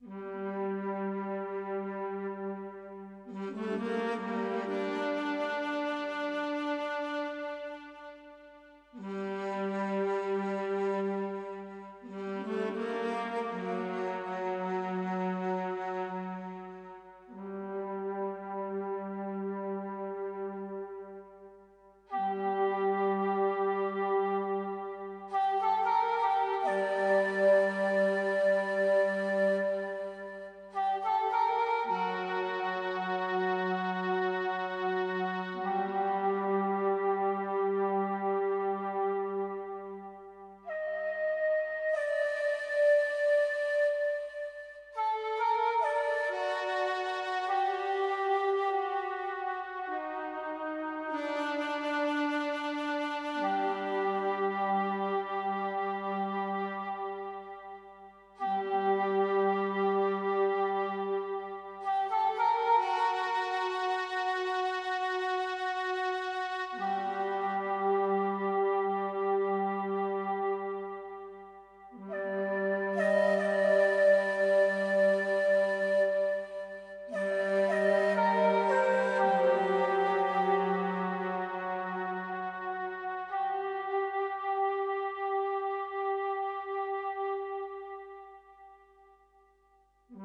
Música ambiental del cuento: El príncipe serpiente
ambiente
melodía
sintonía